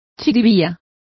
Complete with pronunciation of the translation of parsnip.